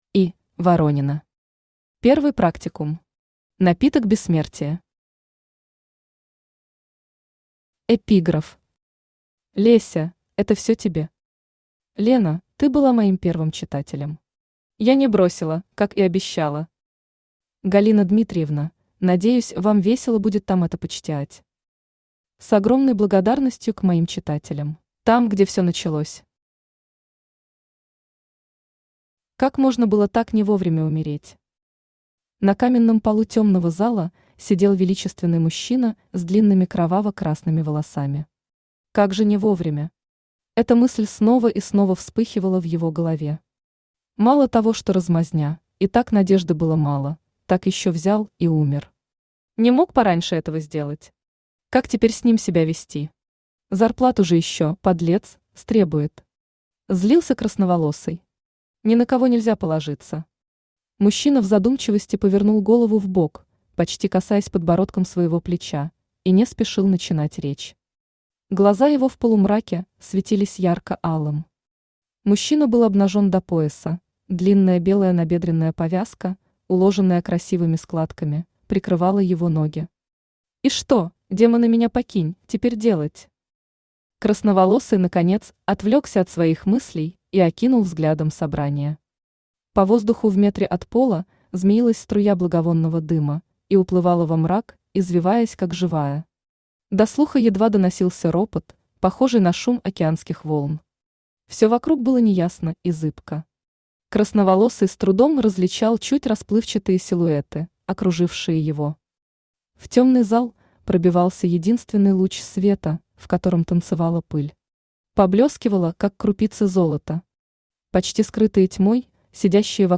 Напиток бессмертия Автор И. Воронина Читает аудиокнигу Авточтец ЛитРес.